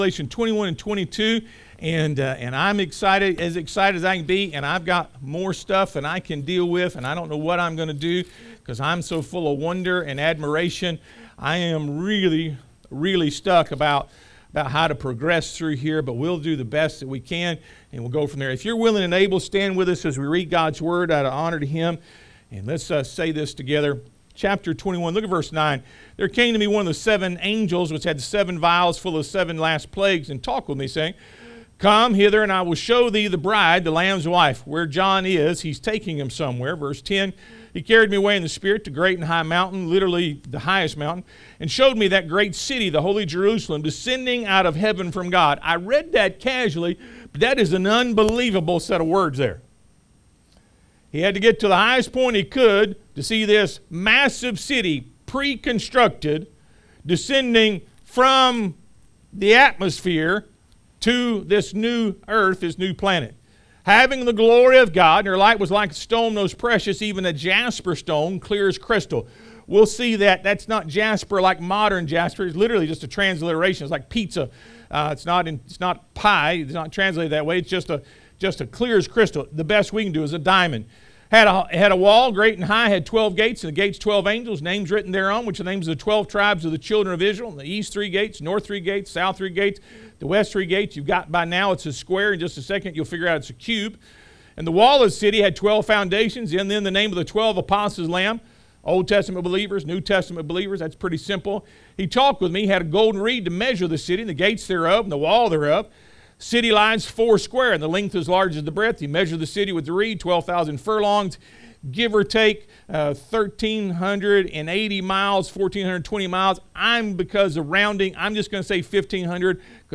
Revelation 21:9-22:5 Service Type: Sunday AM Return of the King The City of God Revelation 21:9-22:5 1.